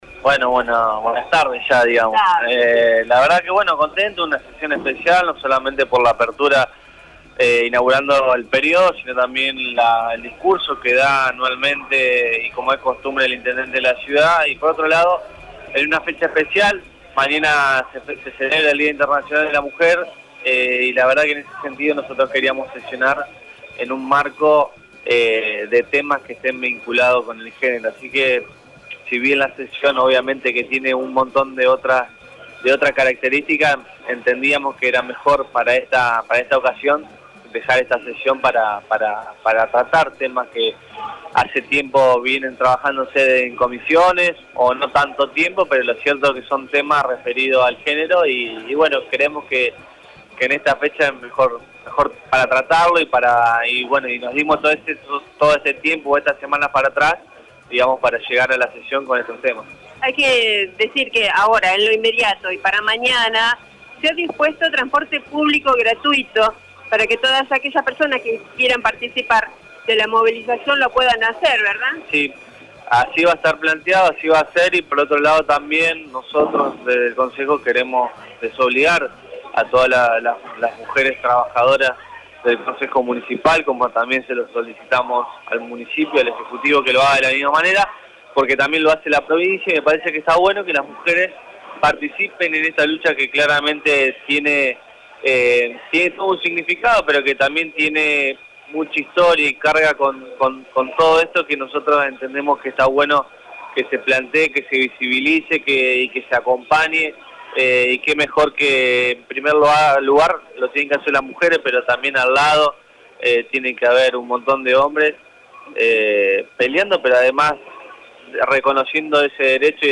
El presidente del concejo, el concejal Sebastián Pignata indicó a Radio EME que la primera sesión del año querían tratar temas de género teniendo en cuenta el día de la mujer.